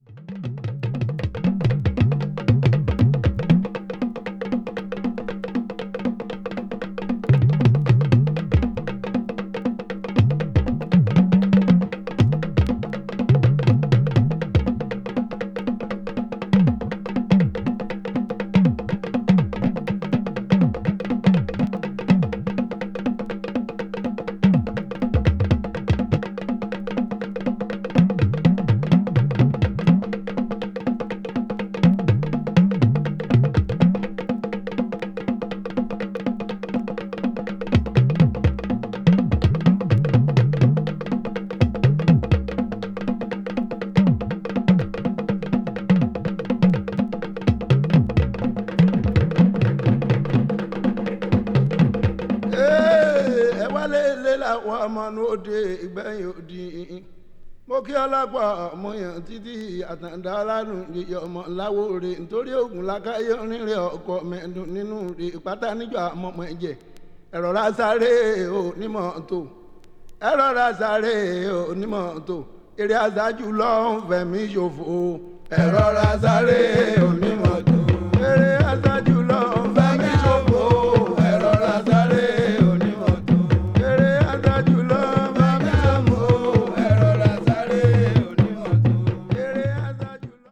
media : VG+/VG+(細かいスリキズによるわずかなチリノイズ/軽いプチノイズが入る箇所あり)
その独特の節回しによるチャントとトーキング・ドラムによる疾走感のあるビートが交互に訪れる展開など